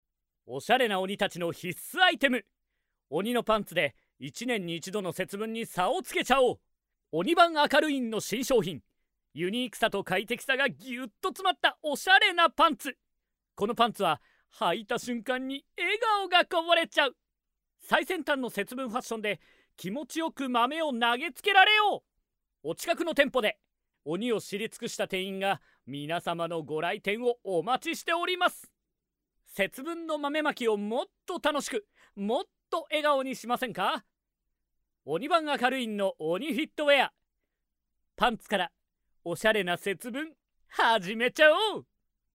声の達人男性ナレーター
明るい
やさしい
さわやか
ゲーム、アニメーション系を用いた動画などへの音声にも親和性が高いタイプの声色と表現です。
ボイスサンプル4（軽快なCM）[↓DOWNLOAD]